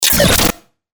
FX-1079-BREAKER
FX-1079-BREAKER.mp3